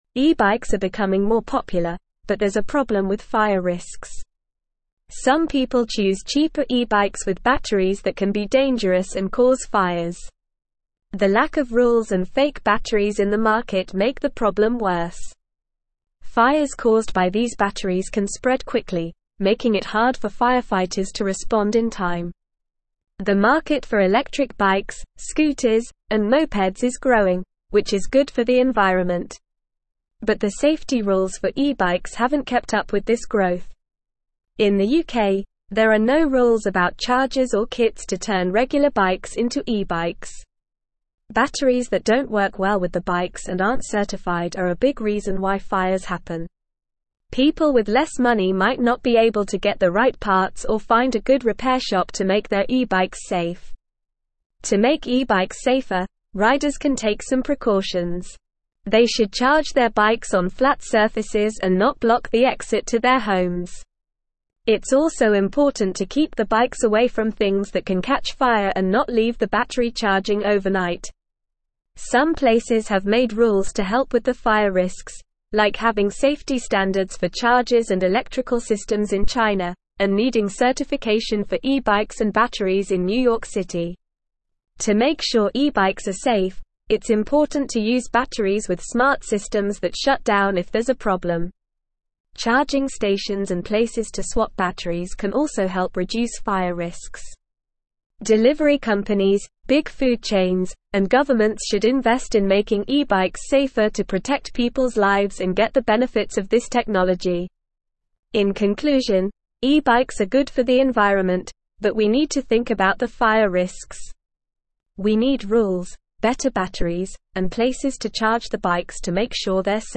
Normal
English-Newsroom-Upper-Intermediate-NORMAL-Reading-Risks-and-Regulations-E-Bike-Safety-Concerns-and-Solutions.mp3